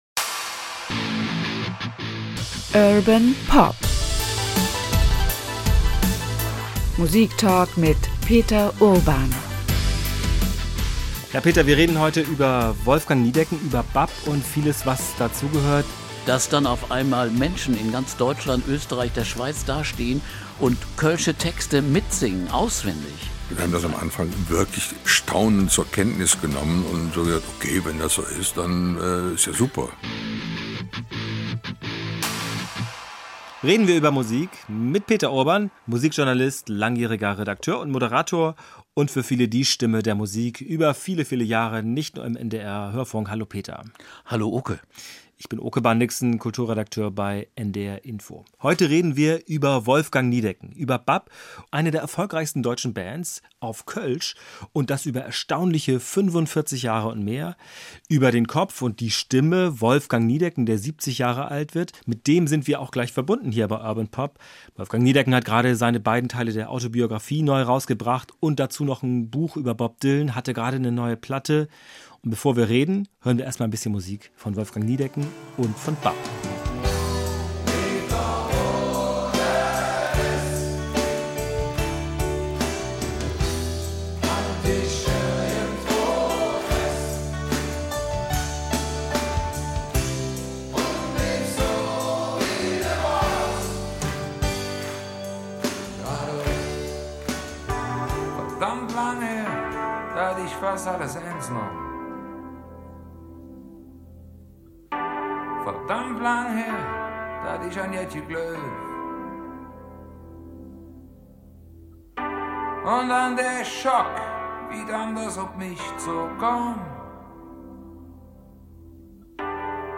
Premiere bei Urban Pop – diesmal ist mit Wolfgang Niedecken erstmals ein Gast im Podcast dabei.